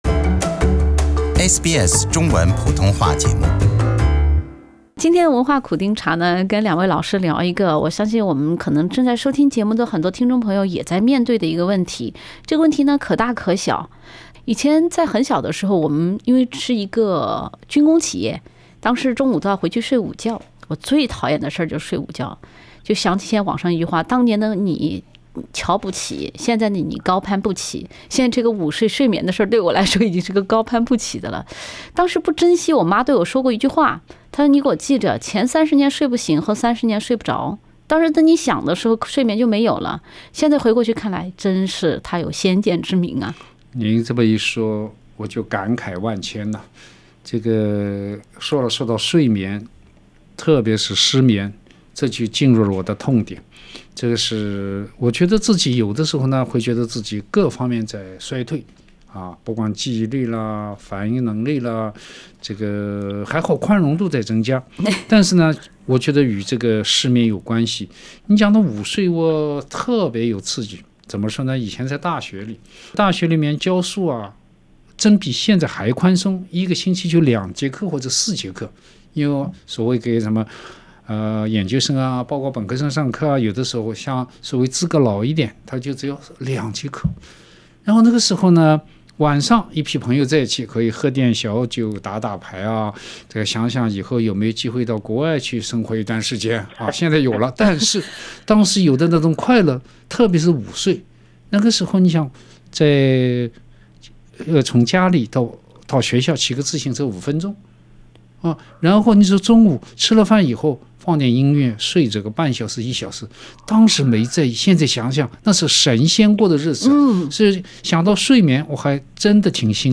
对话除了三人令人捧腹的睡眠经。